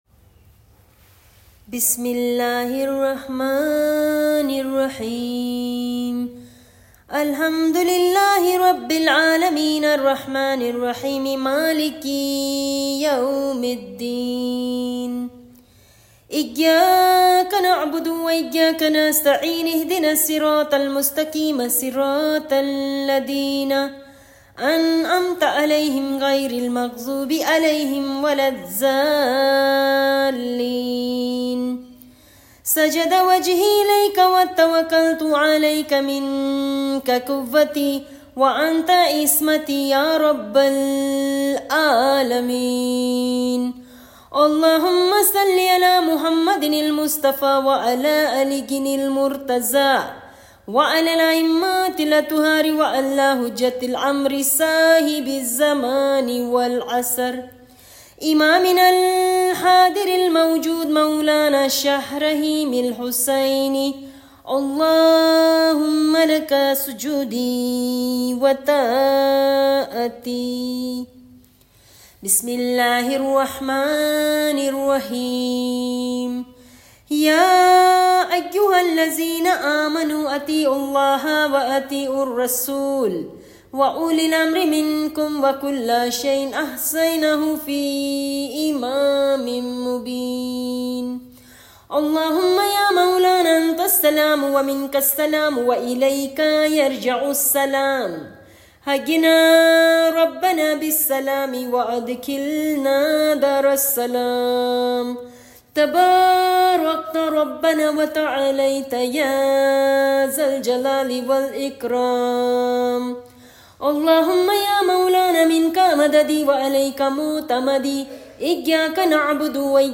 2025-dua-recitation.mp3